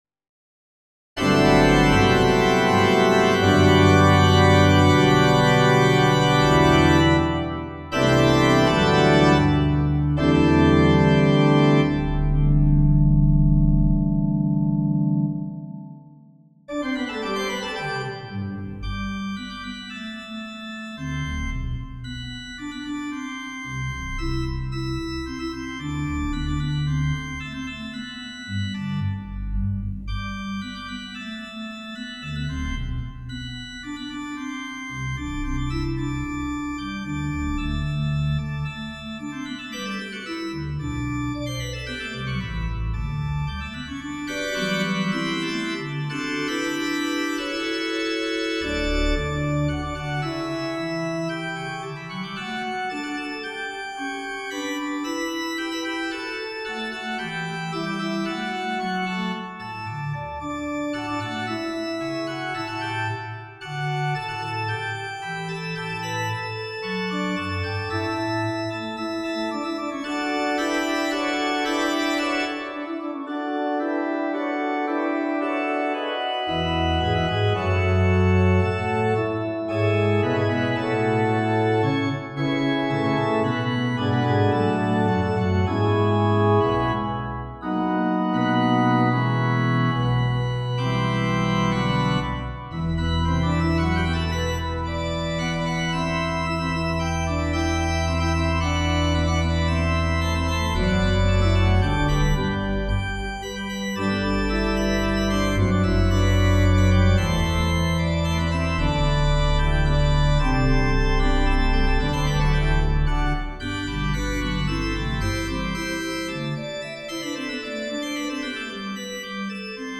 for organ A pleasant subject for this New Year's musing, it is spun out in the traditional tonic and dominant, but also in the mediant major and minor.